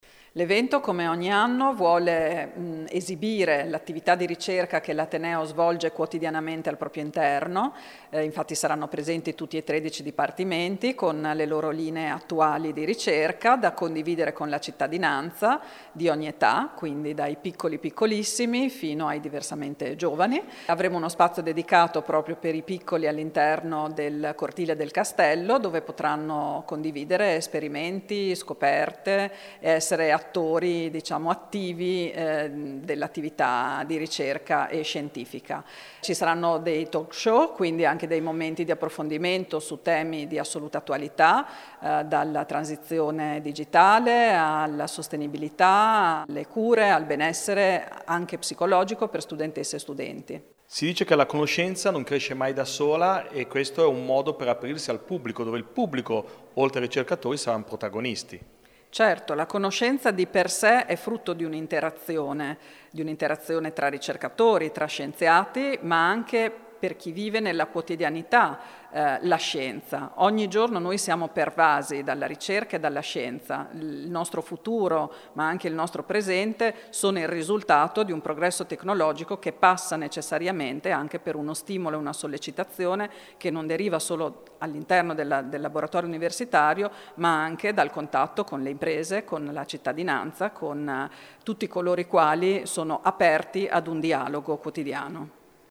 Laura Ramaciotti, Rettrice dell’Università di Ferrara